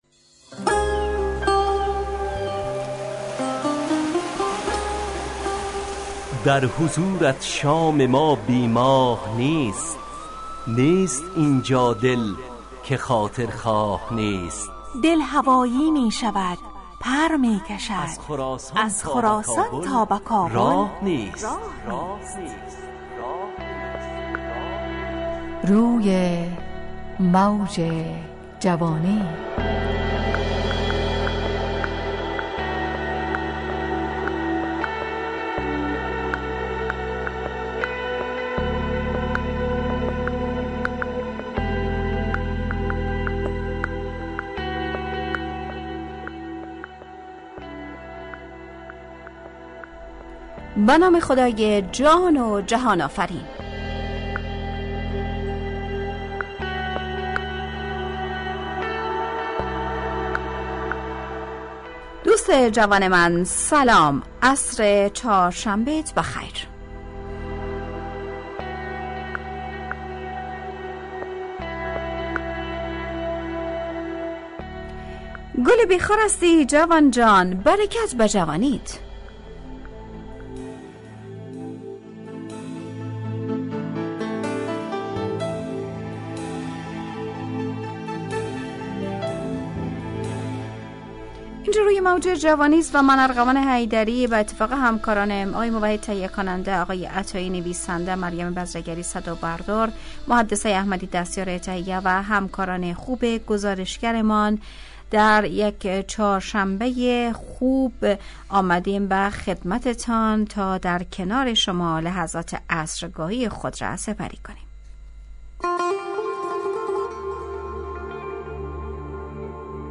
روی موج جوانی، برنامه شادو عصرانه رادیودری.
همراه با ترانه و موسیقی مدت برنامه 70 دقیقه . بحث محوری این هفته (شناخت) تهیه کننده